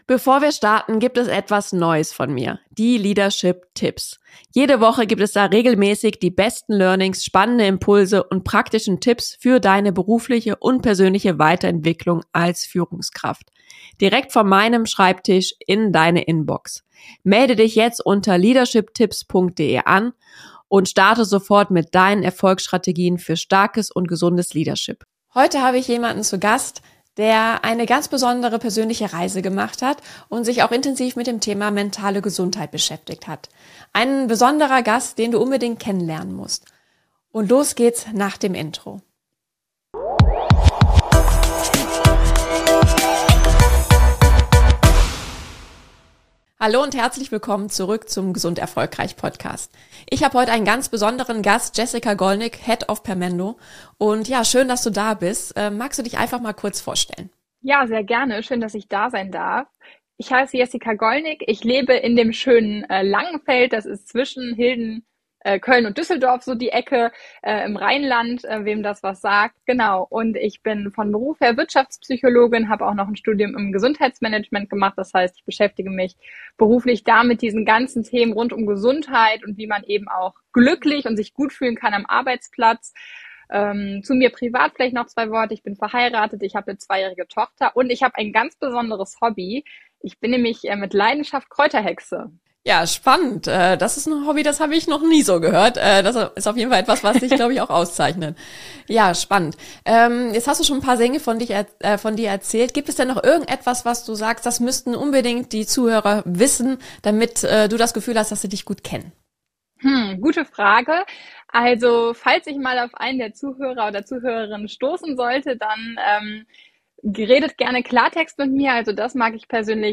Nr. 46 - Mentale Gesundheit ist ein Schlüssel zum Erfolg - Interview